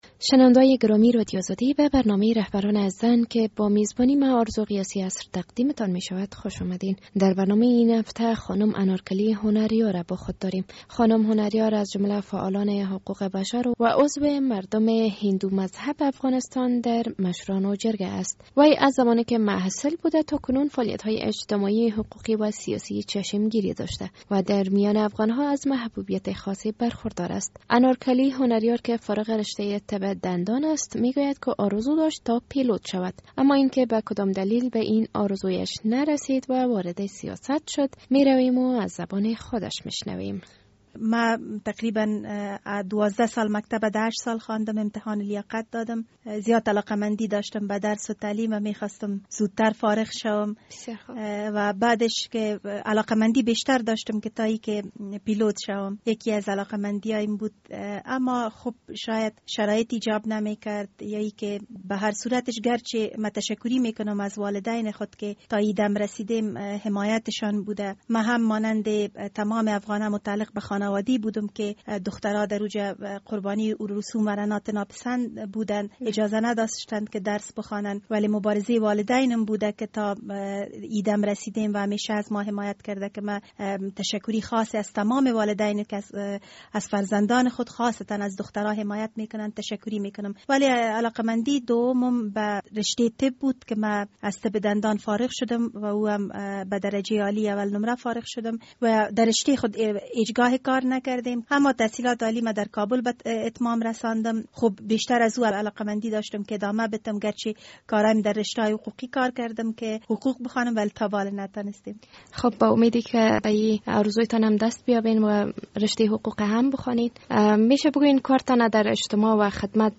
در برنامه این هفته رهبران زن، خانم انارکلی هنریار دعوت شده است.